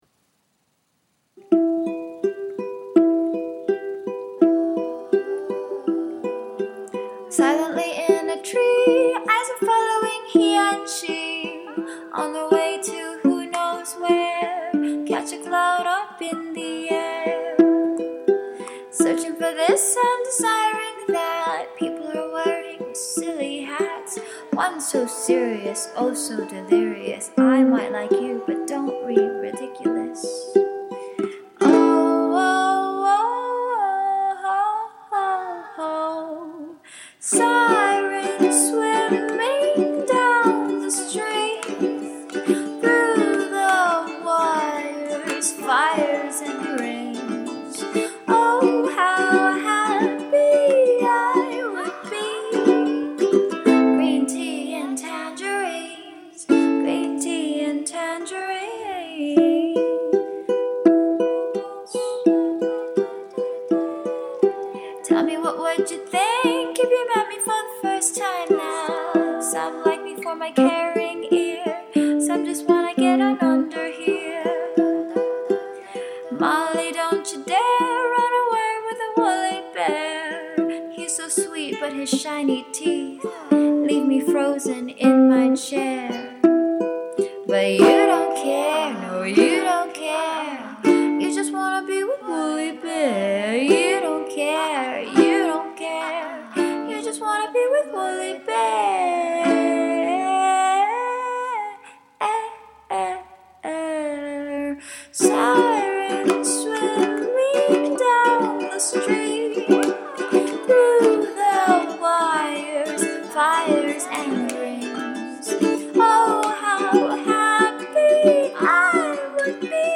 A looney tune about mermaids and sh*t. (Acoustic pop)